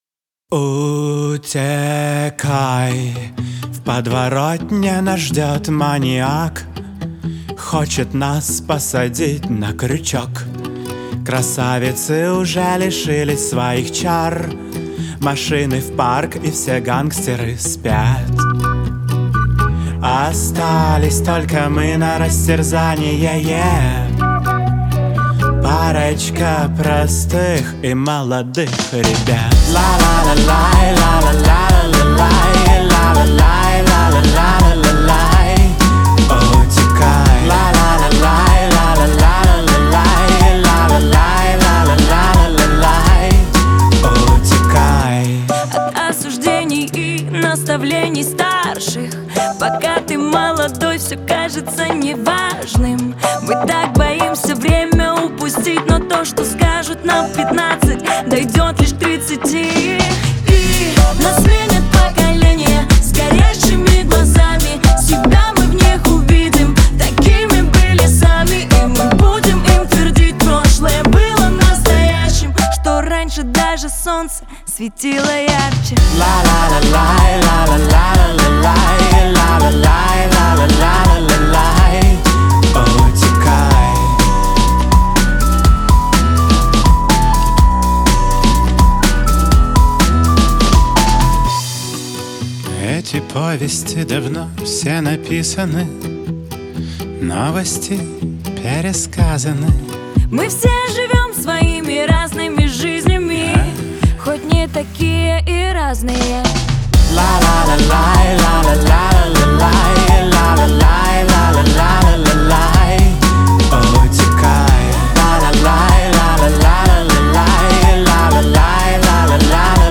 это яркая и мелодичная композиция в жанре поп-рок